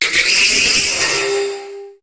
Cri de Katagami dans Pokémon HOME.